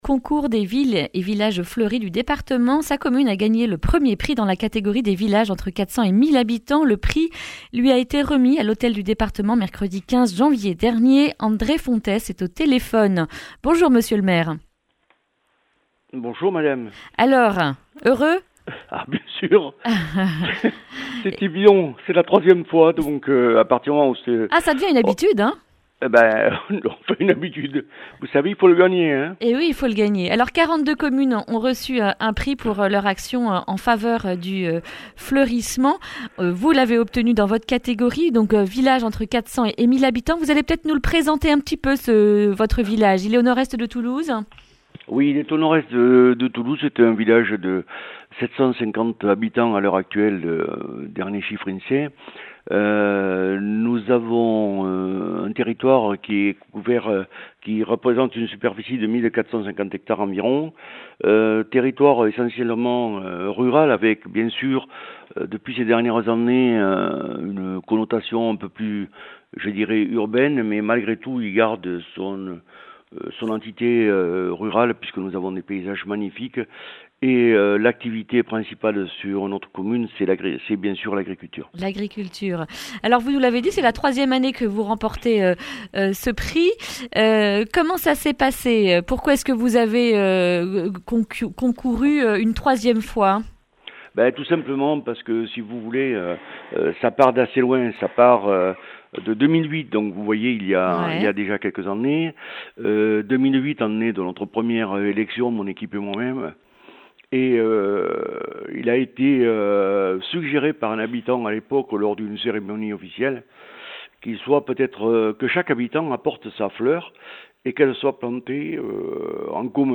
jeudi 23 janvier 2020 Le grand entretien Durée 11 min